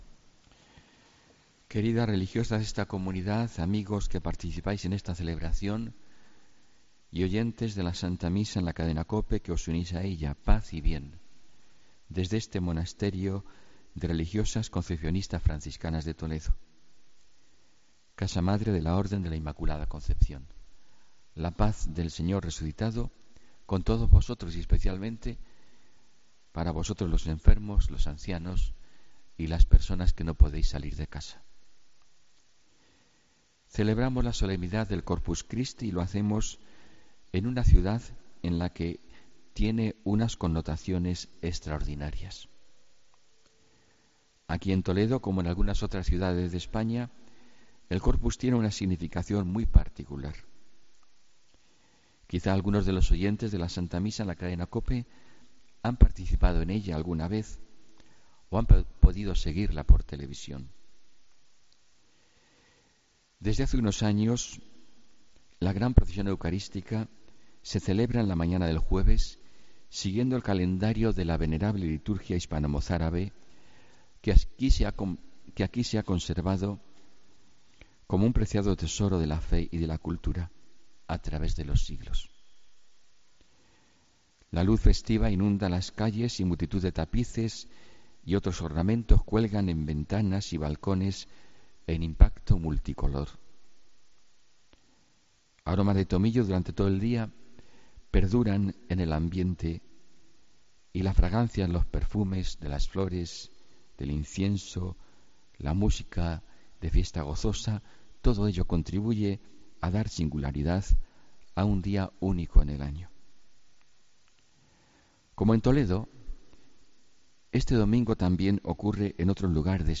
Homilía del domingo 18 de junio